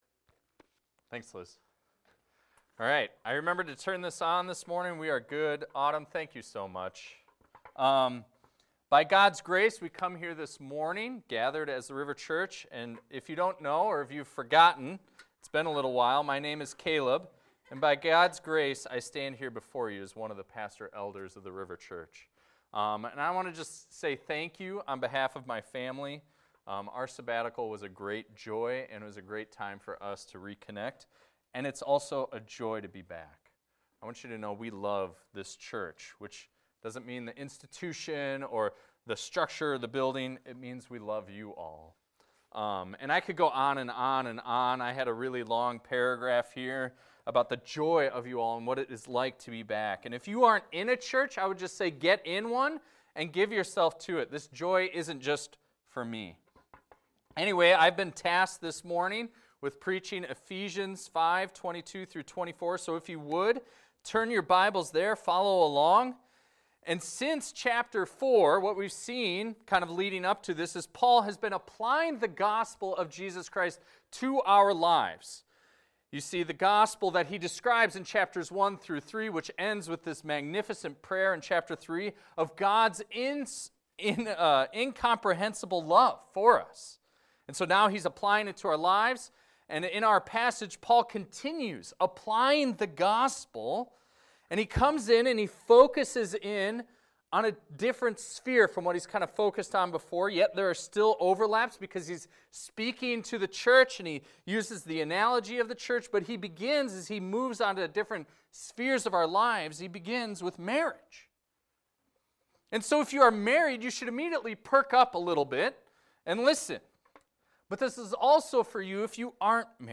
This is a recording of a sermon titled, "Submitting In Love."